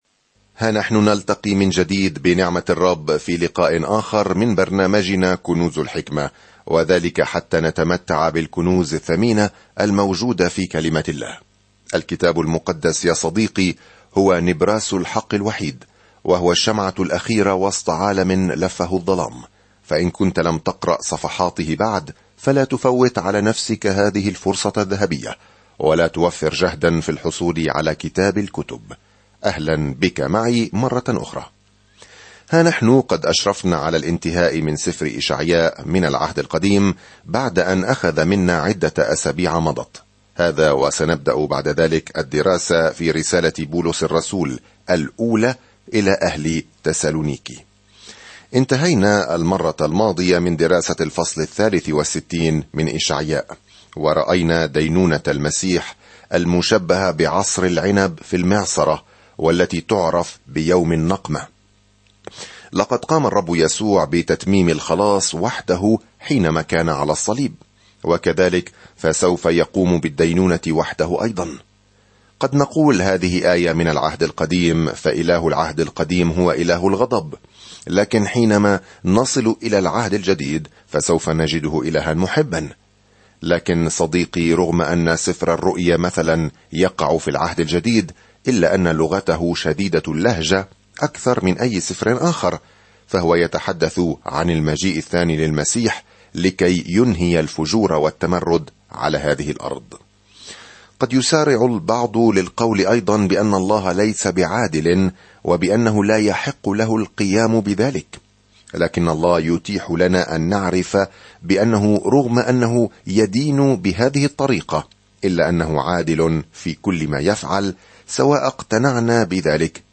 سافر يوميًا عبر إشعياء وأنت تستمع إلى الدراسة الصوتية وتقرأ آيات مختارة من كلمة الله.